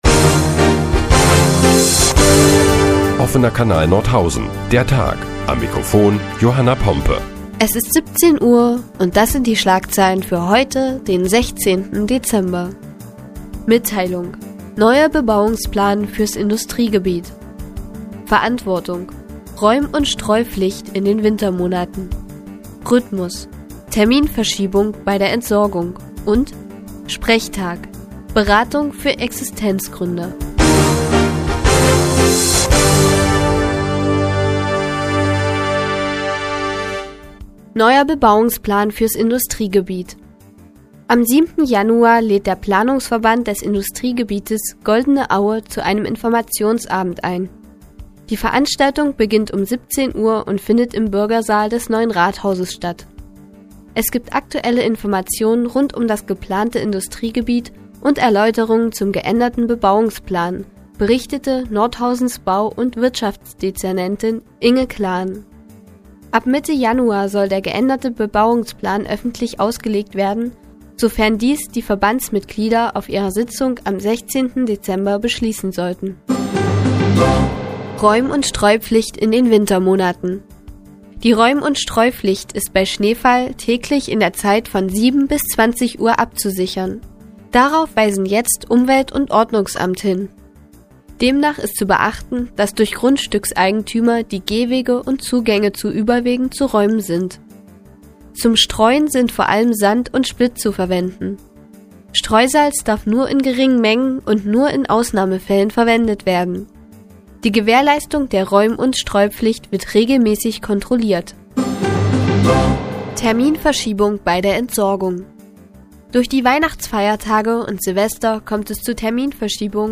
Die tägliche Nachrichtensendung des OKN ist nun auch in der nnz zu hören. Heute geht es unter anderem um den Bebauungsplan des Industriegebietes und Streu- und Räumpflicht in den Wintermonaten.